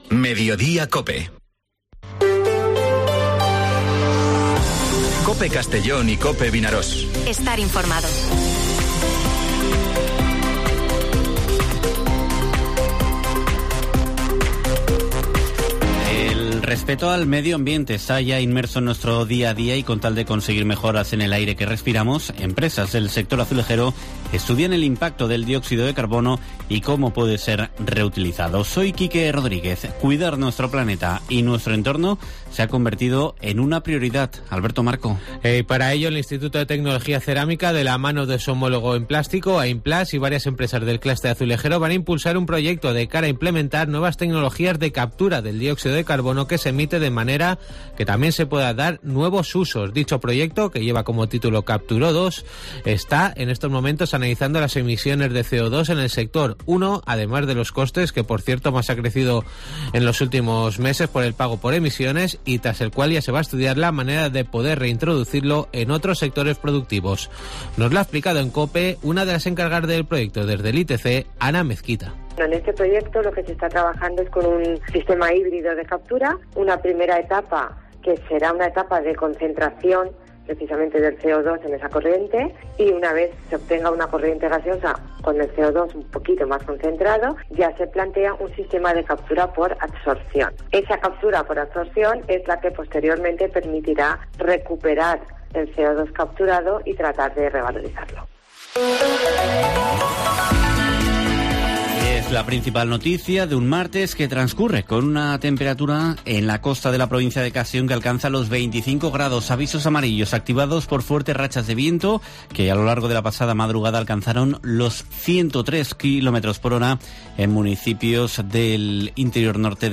Informativo Mediodía COPE en la provincia de Castellón (16/05/2023)